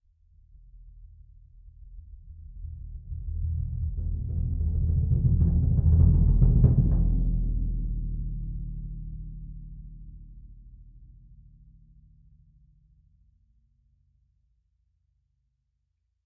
bassdrum_cresc_short.mp3